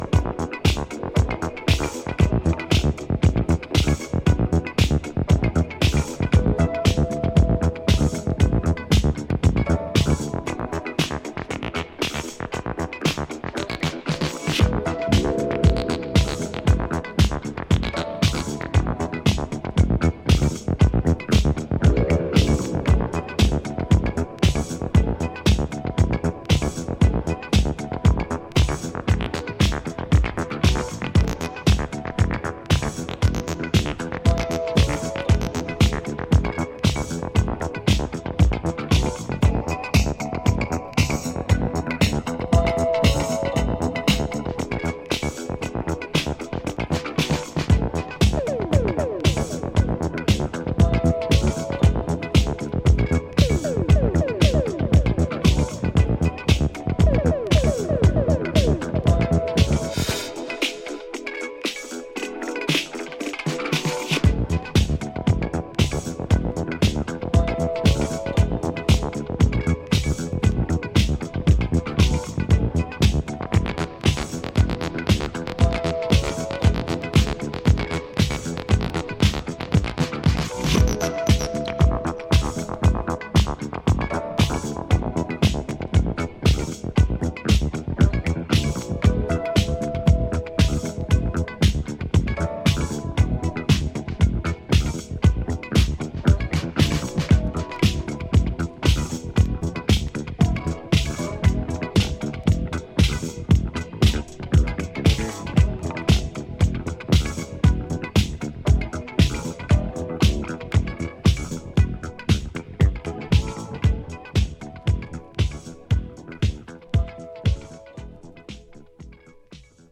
sizzling hot party music
there's a particular emphasis on acid
a proper rubbery acid tweaker which slips in smooth